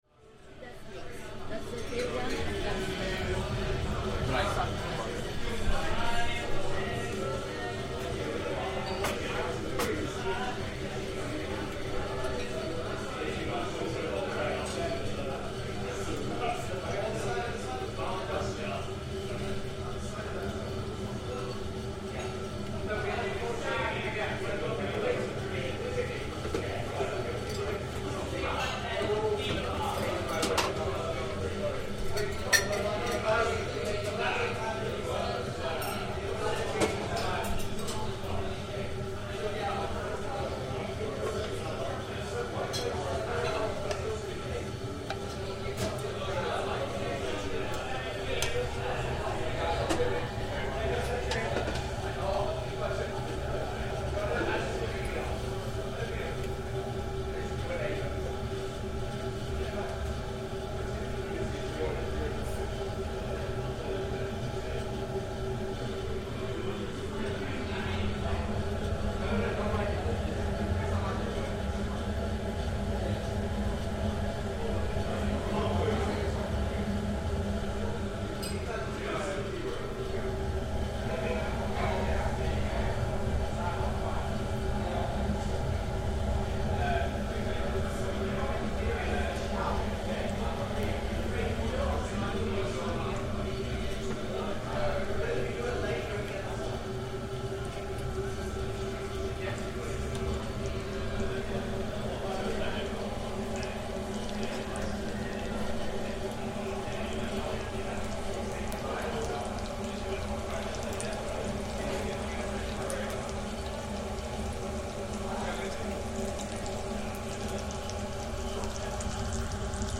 Inside the kitchen at Bar Polski, a close-up recording of traditional Polish pierogi being prepared, including the bubbling of a boiling pan, followed by the frying of bacon and onions - at the end of the recording we leave the small kitchen and head back out into the bustling bar.